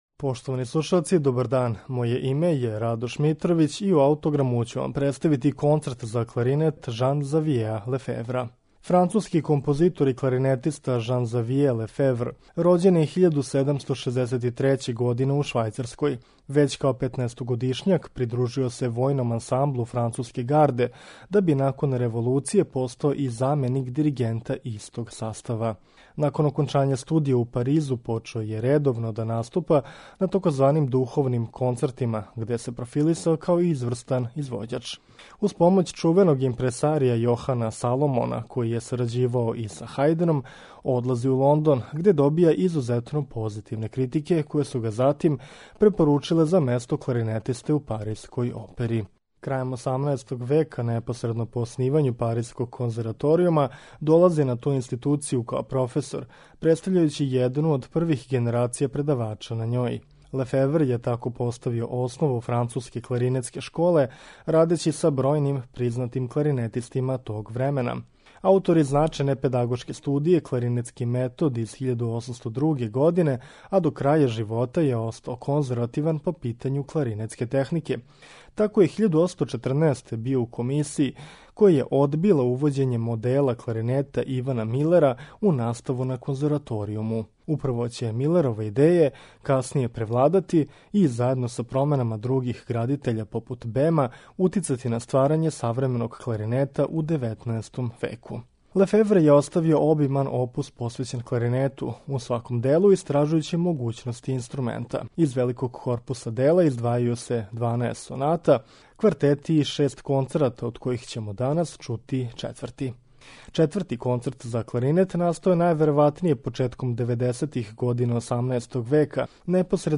Четврти концерт за кларинет Жана Ксавијеа Лефевра